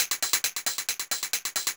Index of /musicradar/ultimate-hihat-samples/135bpm
UHH_ElectroHatC_135-03.wav